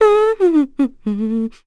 Juno-Vox_Hum.wav